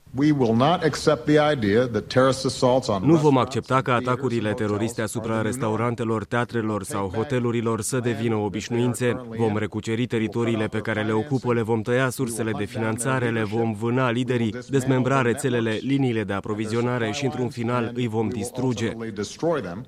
Între timp, Marea Britanie anunţă că va decide în următoarele zile dacă se va implica în raidurile aeriene din Siria,iar preşedintele american Barck Obama şi-a ascuţit tonul la adresa Statului Isalmic şi a altor grupări teroriste:
insert_obama.mp3